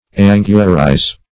Angurize \An"gur*ize\